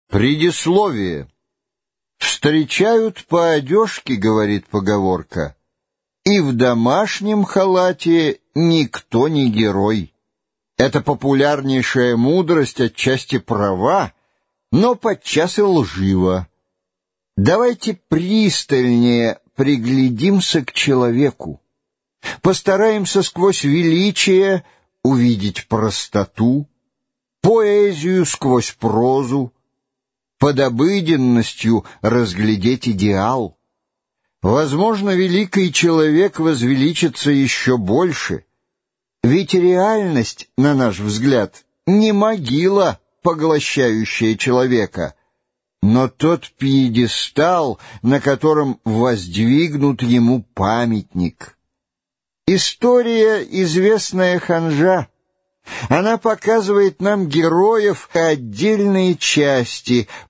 Аудиокнига Генрих IV | Библиотека аудиокниг